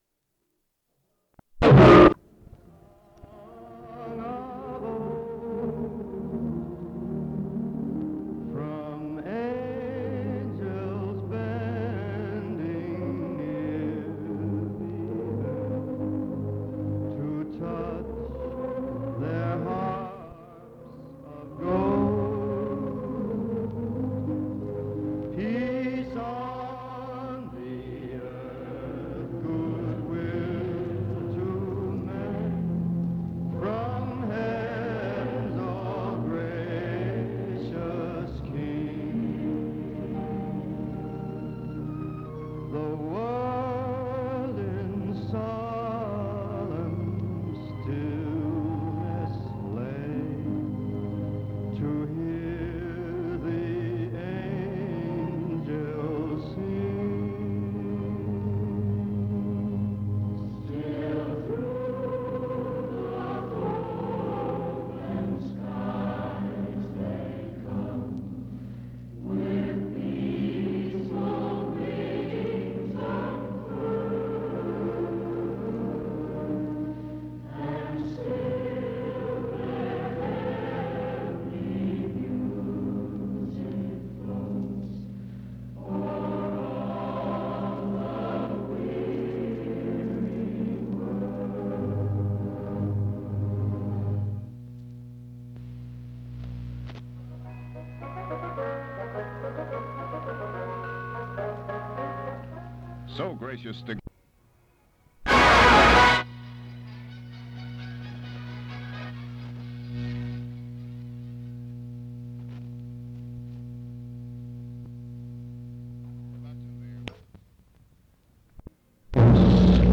Description Speech by Dr. Wernher von Braun to the Southern (Nurserymens?) Association on side 1. Pertains to early NASA projects and future plans, circa 1960. Speech taped over something else, audio very faint at points. Recorded Christmas radio program on side 2.
Open reel audiotapes